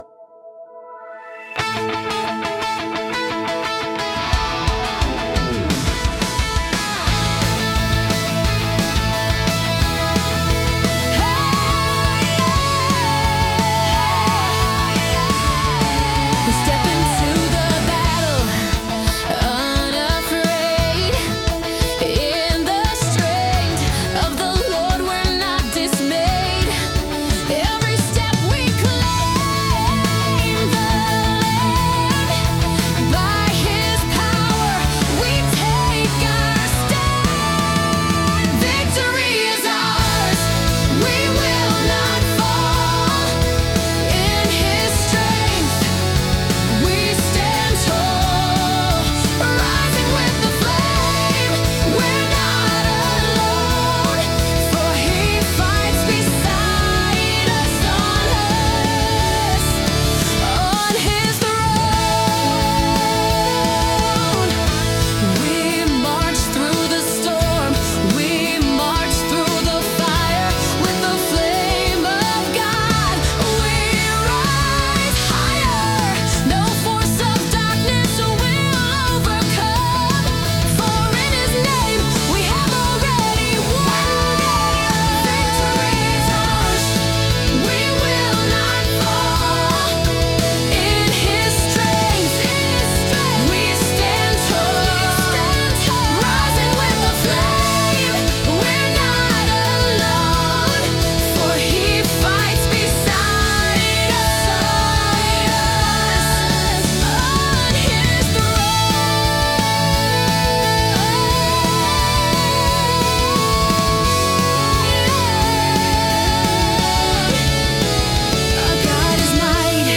prophetic worship song
With powerful lyrics and driving rhythm
• Genre: Prophetic Worship / Christian Rock